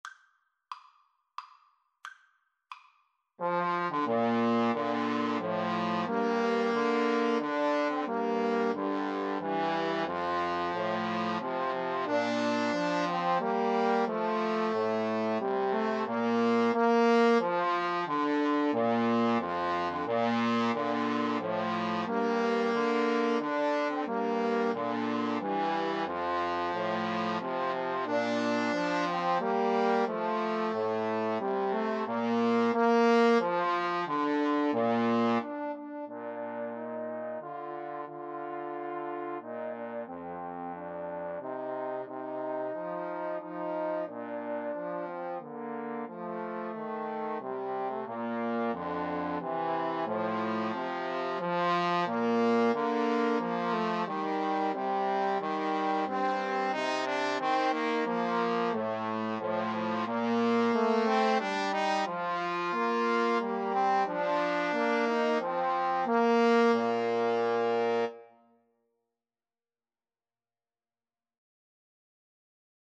3/4 (View more 3/4 Music)
Bb major (Sounding Pitch) (View more Bb major Music for Trombone Trio )
Maestoso = c.90
Trombone Trio  (View more Intermediate Trombone Trio Music)